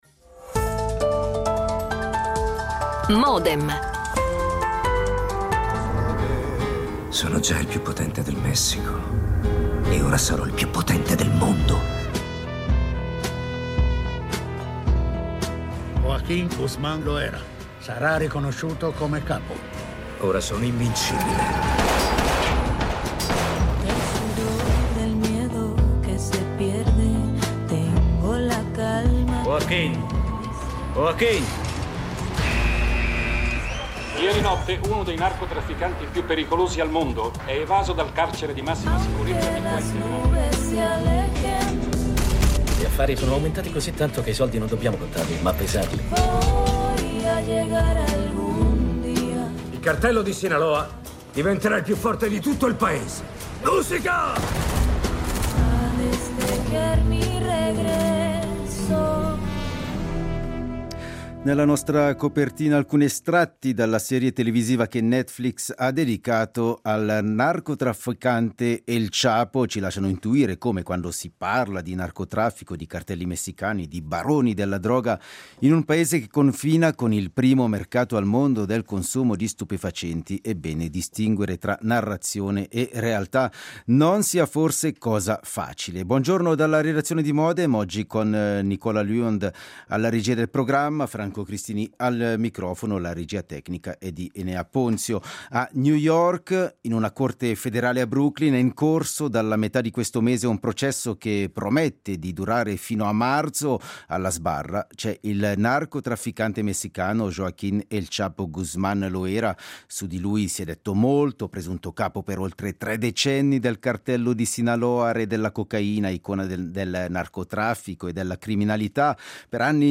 Interviste registrate
L'attualità approfondita, in diretta, tutte le mattine, da lunedì a venerdì